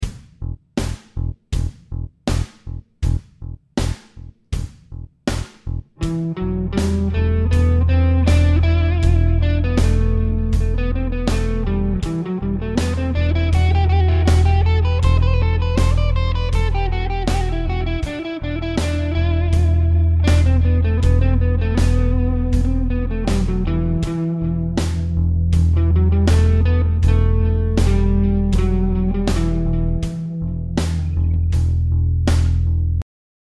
The bass is just playing the E note in all examples.
Aeolian
This is the same as the minor scale.